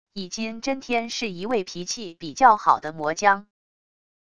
以津真天是一位脾气比较好的魔将wav音频生成系统WAV Audio Player